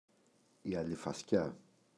αλιφασκιά, η [alifaꞋsca]